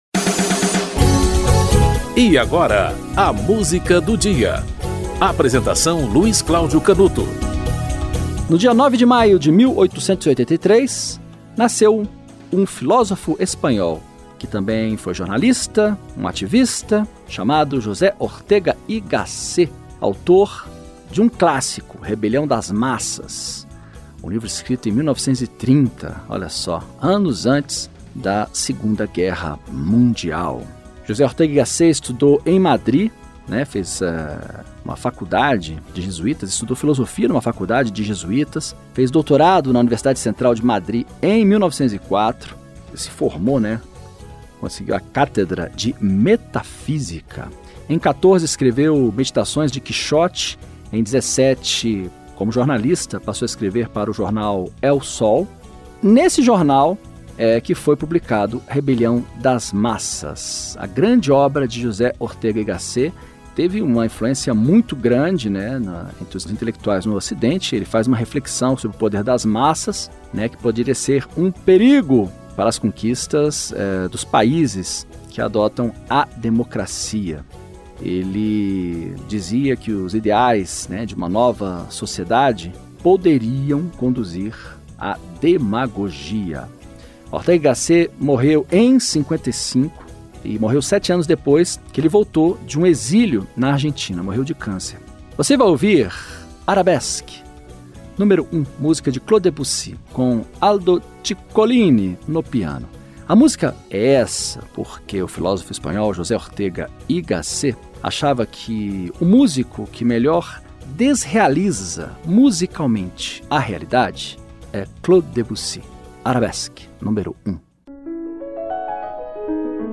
Aldo Ciccolini - Arabesque, n. 1 (Claude Debyussy)
O programa apresenta, diariamente, uma música para "ilustrar" um fato histórico ou curioso que ocorreu naquele dia ao longo da História.